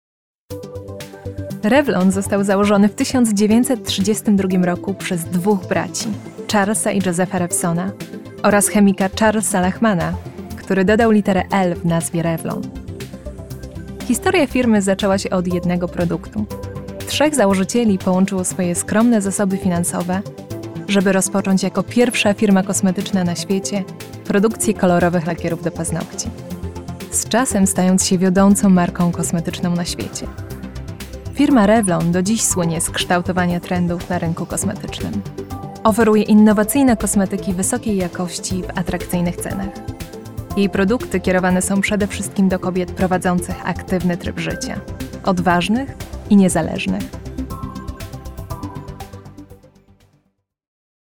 Narration
RODE NT1A, cabine vocale entièrement isolée dans un placard. Interface Scarlett 2i2, MacBook Air, Adobe Audition
Mezzo-soprano